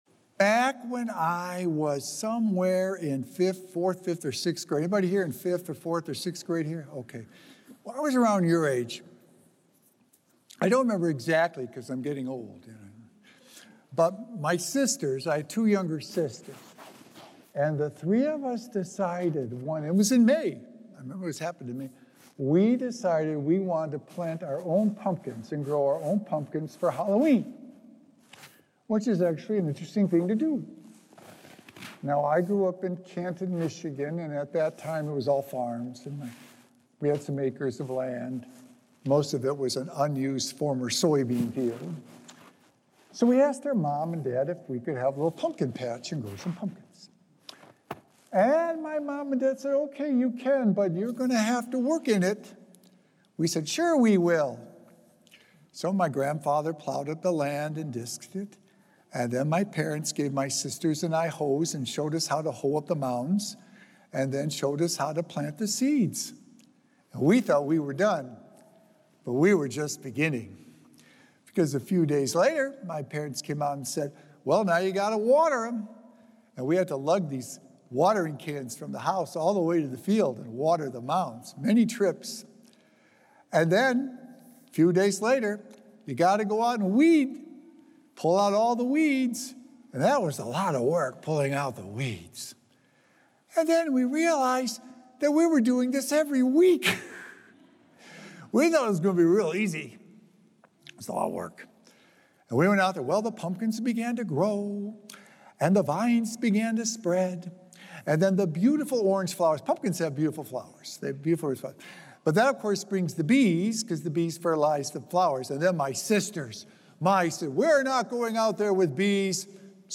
Sacred Echoes - Weekly Homilies Revealed
Recorded Live at St. Malachy Catholic Church on Sunday, May 25th, 2025